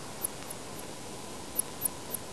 Echolocation calls
The echolocation call is a relatively short constant frequency signal with a brief frequency-modulated tail.
Call duration: 5.4 ms; Constant frequency at 120.3 kHz on average in Sichuan and Guizhou, sweep at end terminating at 104.5 kHz.